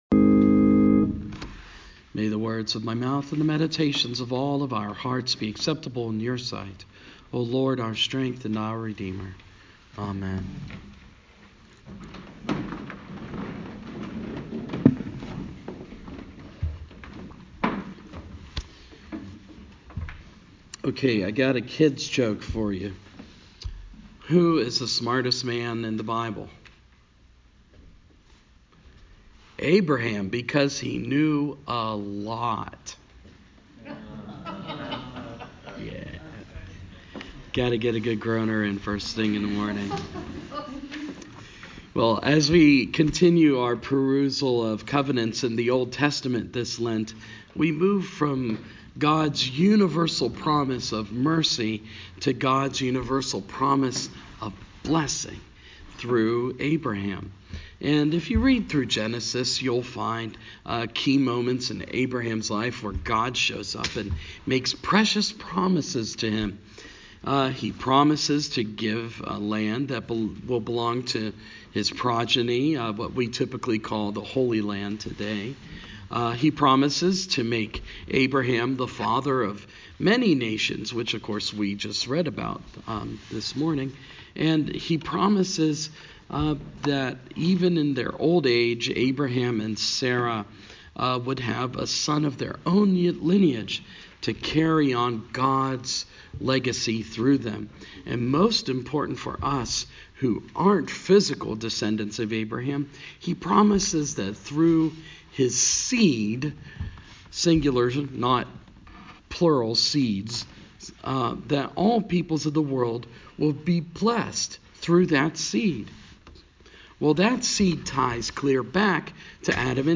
Our readings today point us in the direction of God's covenants with Abraham. And we are reminded that we are true children of Abraham when we have a living faith in Jesus Christ. (Please forgive my voice, as I am recovering from the flu in this recording.)